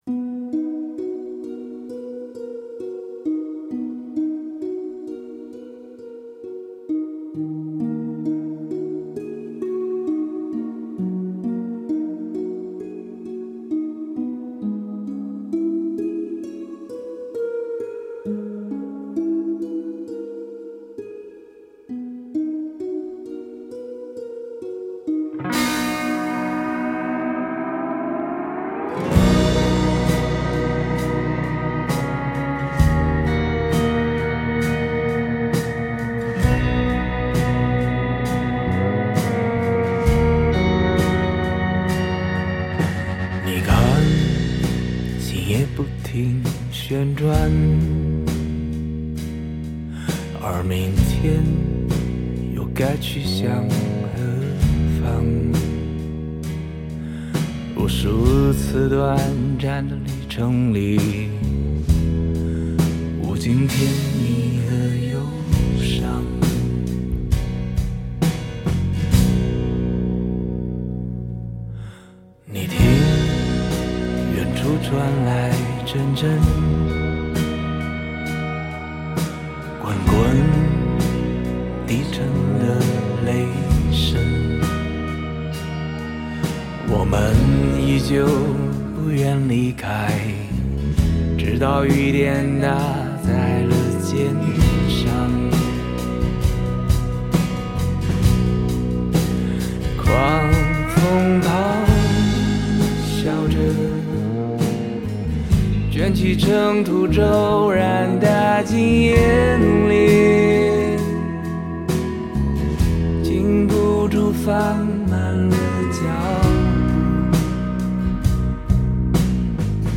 Ps：在线试听为压缩音质节选，体验无损音质请下载完整版
吉他
贝斯
鼓
录音棚：成都尖音坊录音棚